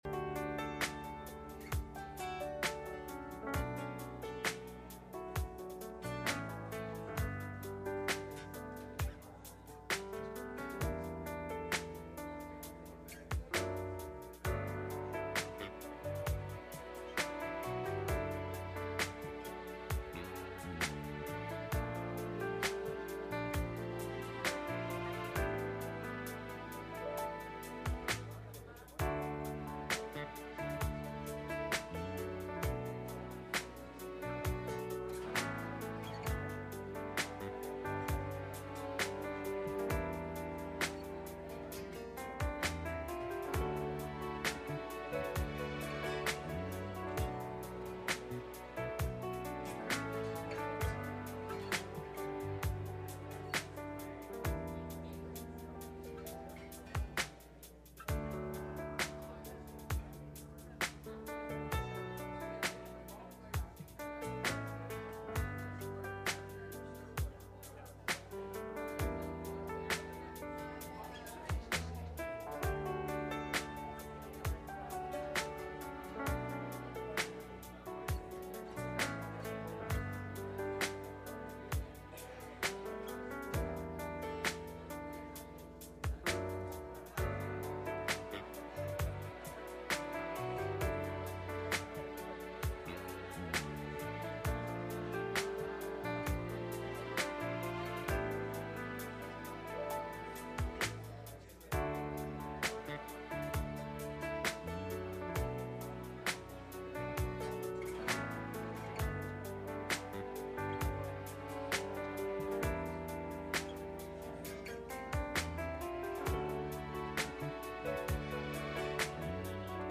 Passage: Luke 19:1-10 Service Type: Sunday Morning